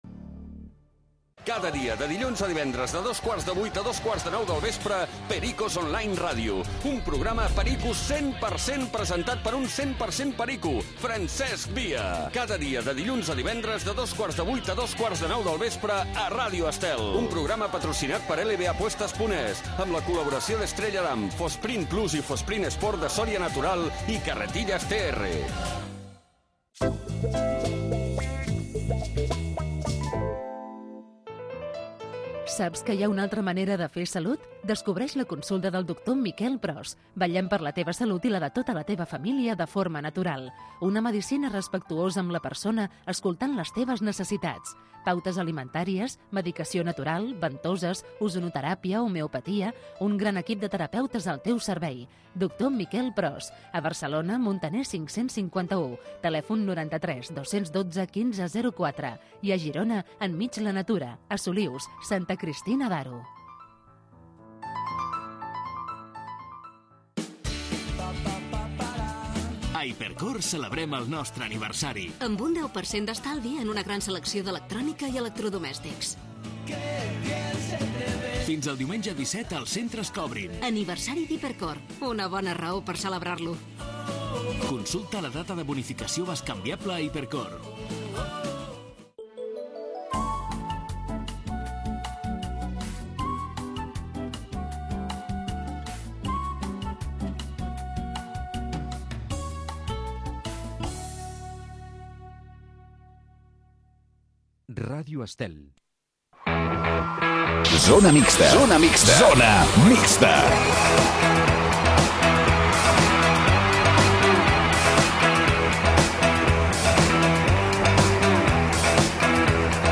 Programa diari dedicat al món de l'esport. Entrevistes amb els protagonistes de l'actualitat poliesportiva.